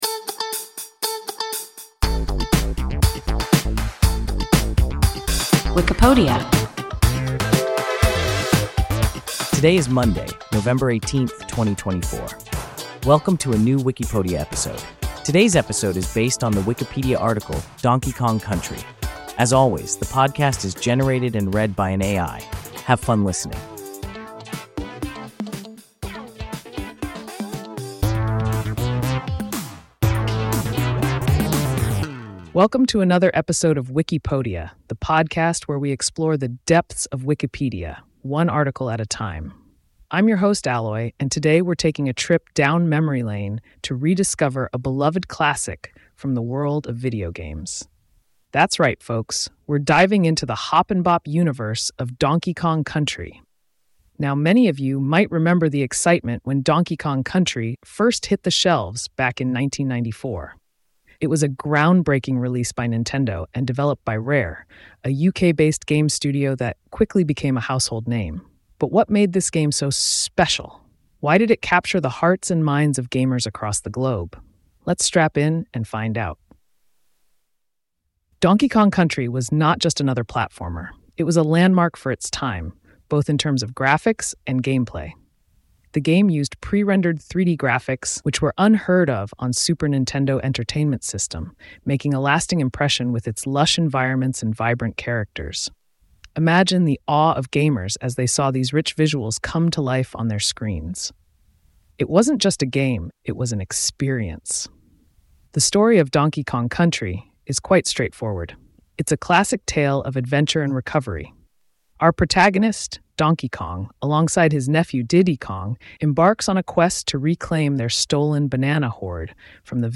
Donkey Kong Country – WIKIPODIA – ein KI Podcast